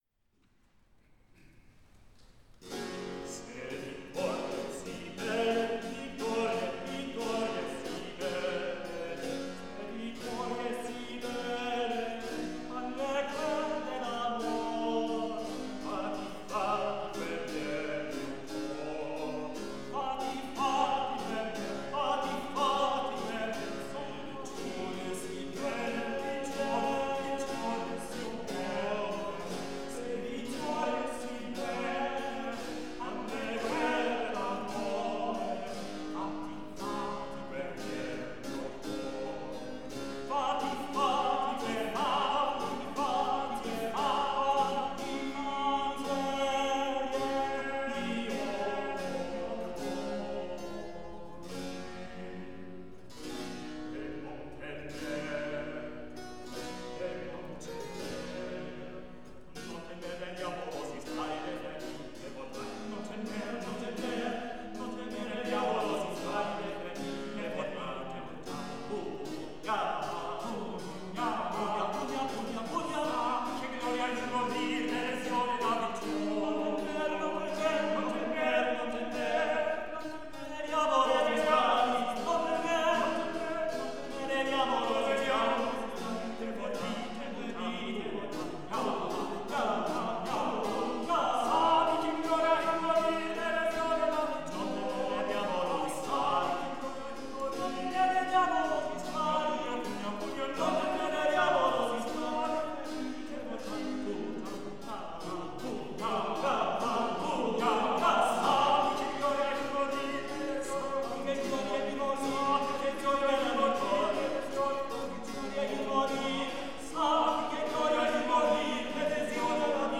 A Memorable Concert
2 sopranos, alto, tenor, and bass
Millennium Ensemble
harpsichordist
to play continuo on some of the early seventeenth-century pieces.
As I wrote in the program notes about the following three pieces, they “were published as ‘madrigals,’ but display much of the features of the early Baroque: excessive chromaticism, virtuosity, and use of the basso continuo for support, or, as in Se vittorie si belle, as an integral part of the ensemble.”
A humorous note: as the madrigal “Zefiro torna” moves to tragic utterances at “Ma per me, lasso,” a siren is heard outside the venue (Seattle’s Gethsemane Lutheran Church, across from the now-defunct Seattle Bus Depot).
I left in the applause after this section to indicate how well-received this concert was